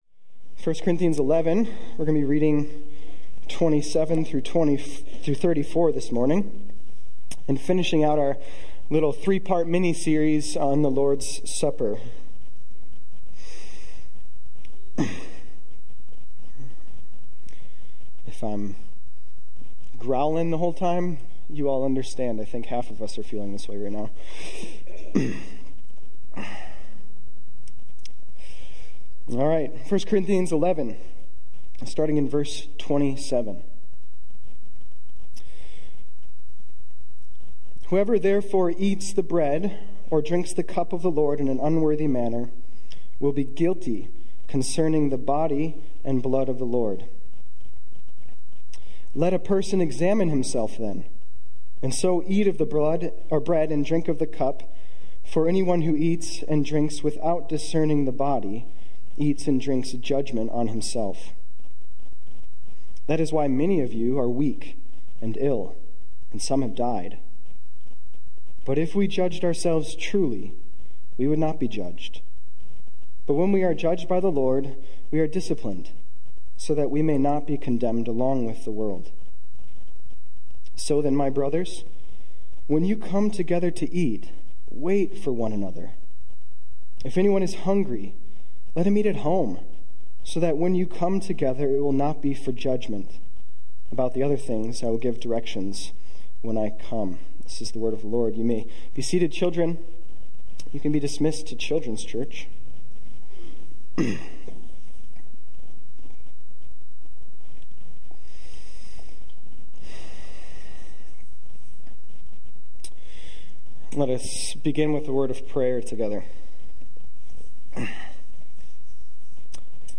Sermons Archive - Immanuel Baptist Church - Wausau, WI